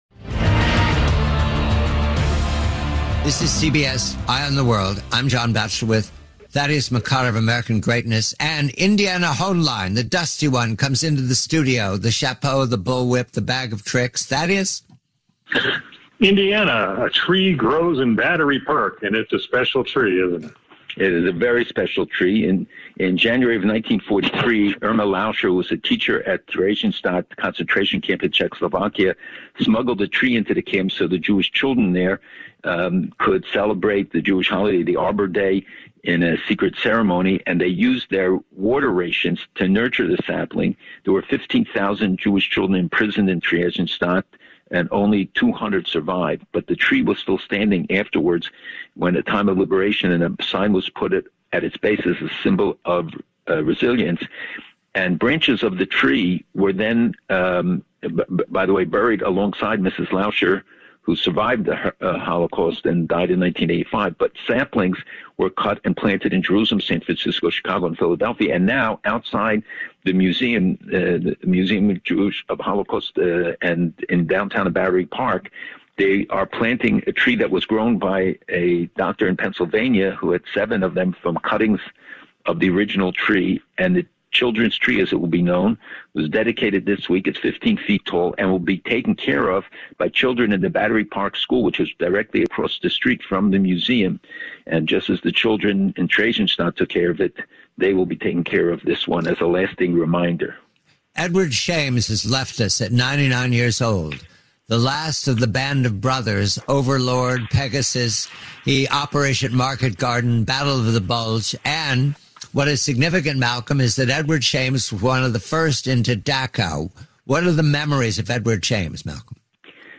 GUEST NAMES: John Batchelor (Host), Thaddeus McCotter of American Greatness, and Malcolm Hoenlein